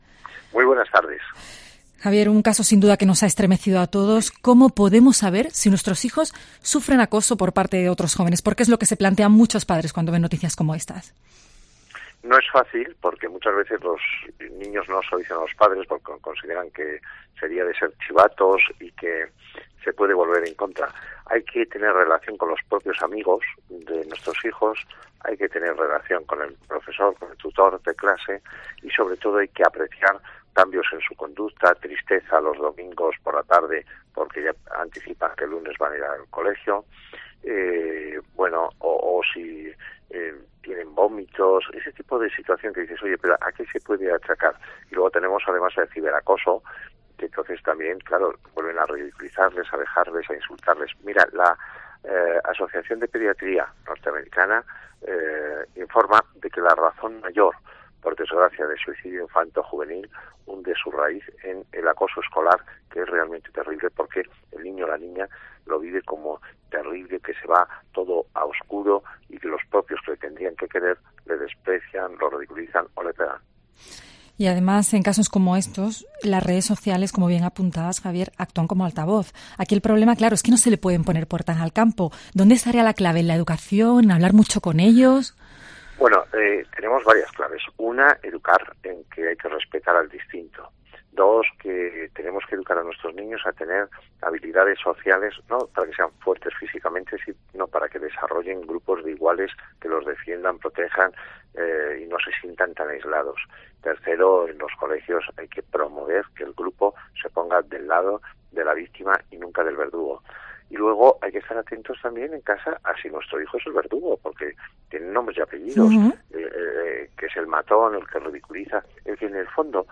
Escucha aquí la entrevista completa en LA LINTERNA DE ANDALUCÍA, donde Urra ha hecho también referencia a los agresores, “en algunas ocasiones son niños o jóvenes con familias desestructuradas, aunque no siempre se cumple este perfil”.